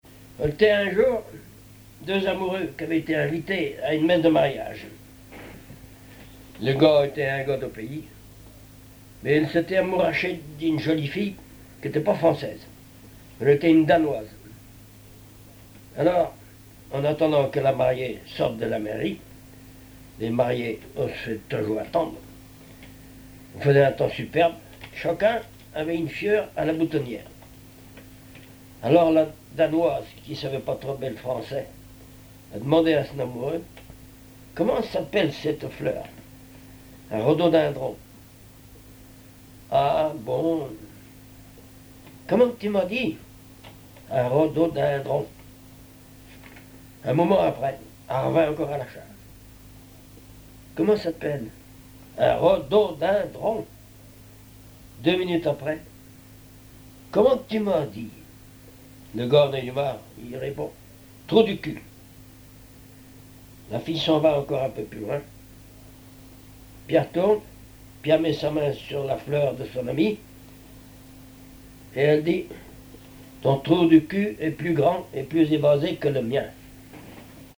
Genre sketch
contes, récits et chansons populaires
Catégorie Récit